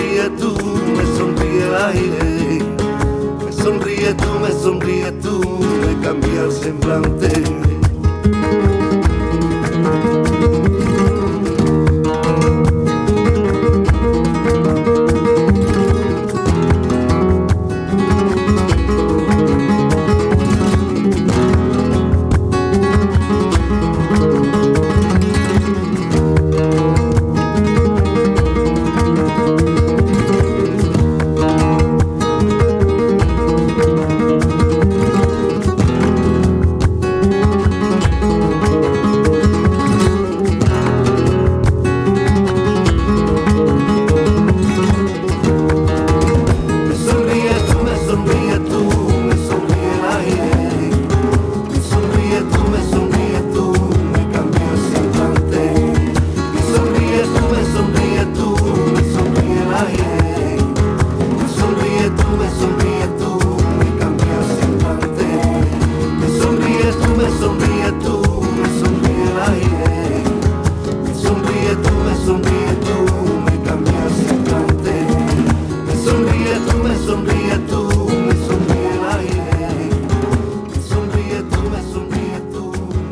Soleá por Bulerías 4:33